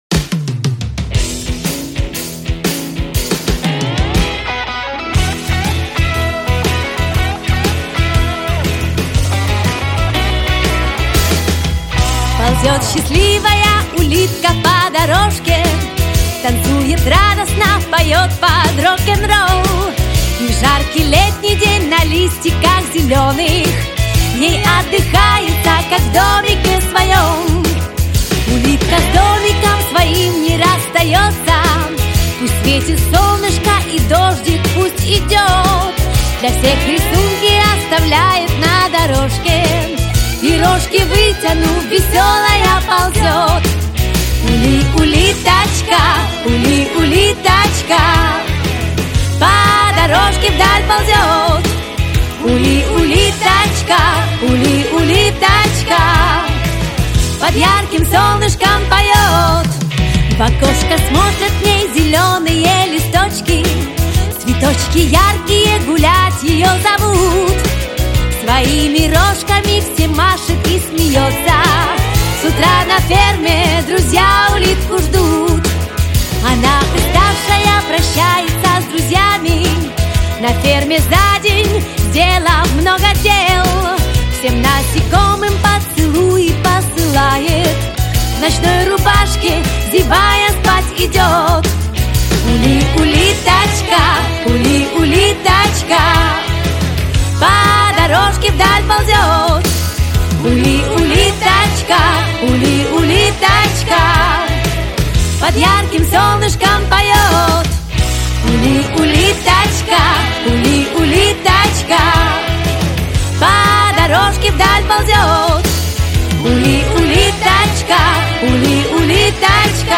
• Категория: Детские песни
малышковые